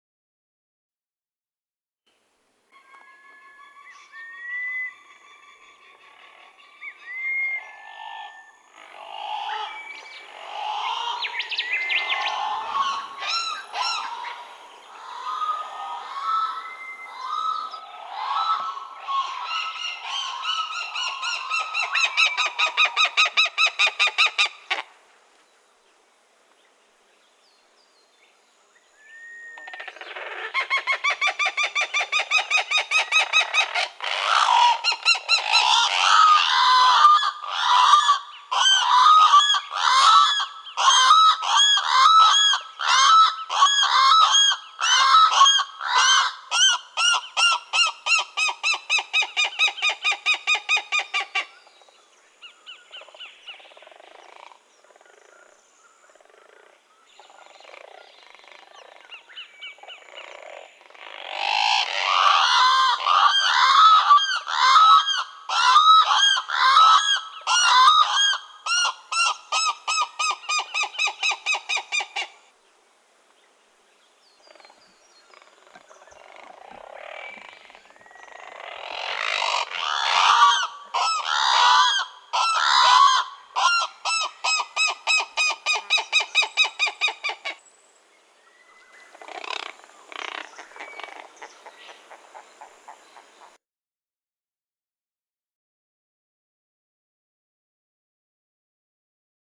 Unique Australian Bird Sounds
channel billed cuckoo
08-channel-billed-cuckoo.mp3